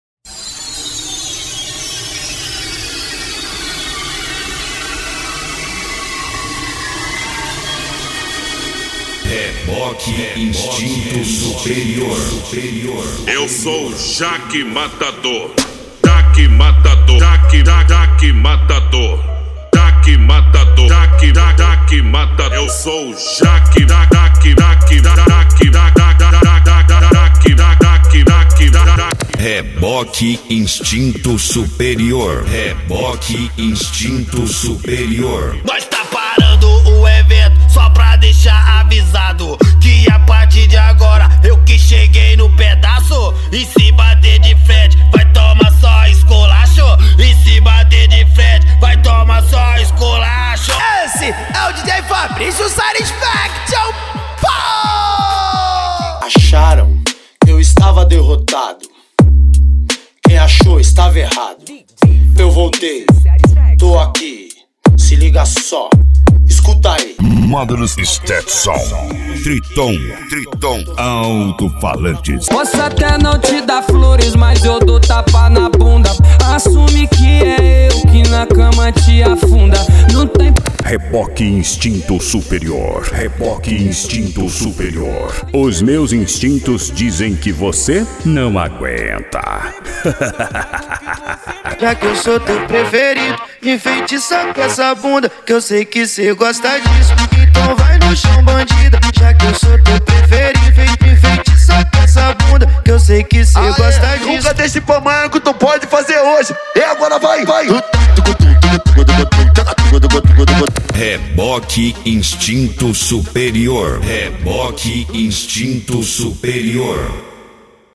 Bass
Mega Funk